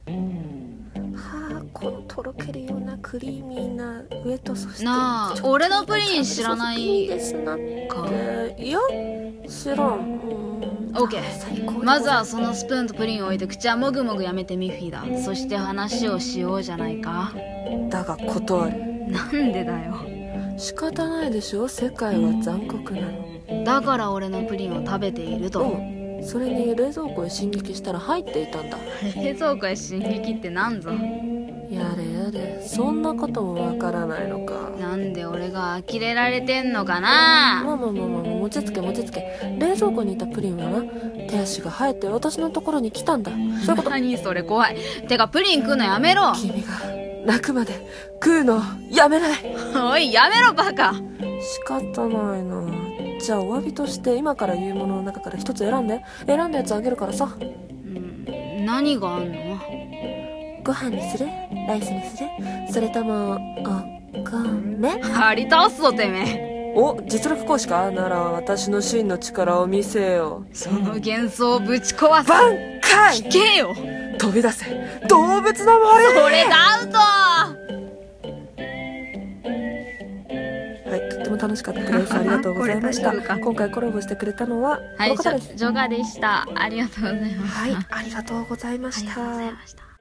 【ギャグ】色々アウトな会話【掛け合い声劇】